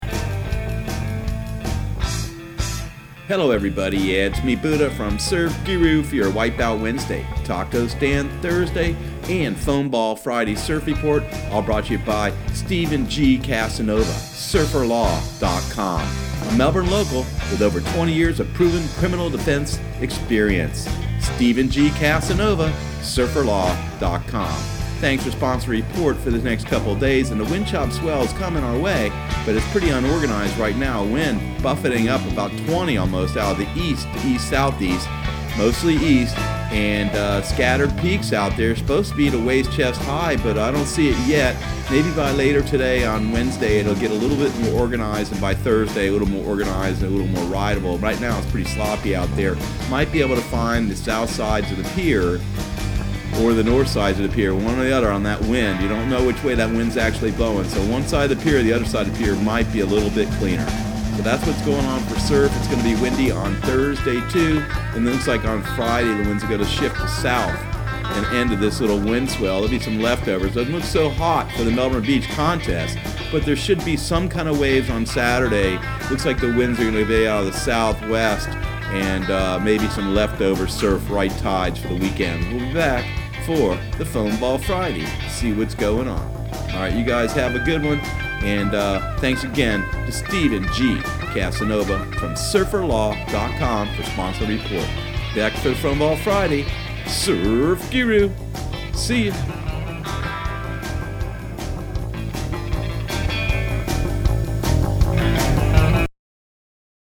Surf Guru Surf Report and Forecast 05/01/2019 Audio surf report and surf forecast on May 01 for Central Florida and the Southeast.